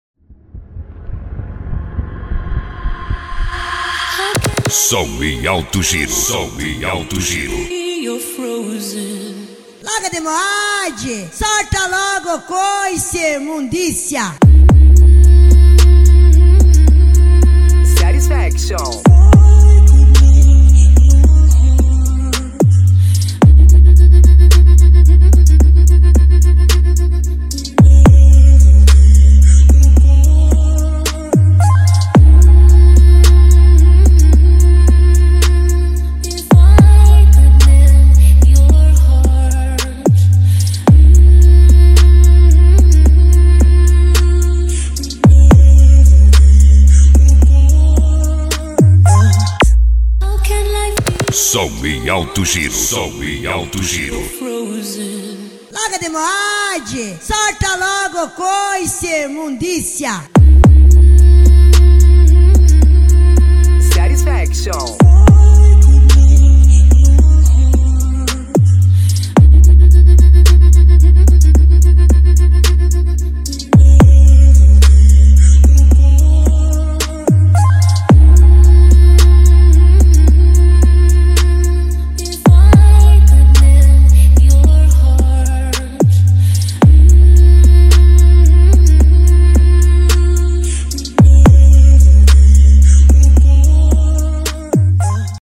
Bass
Funk
Mega Funk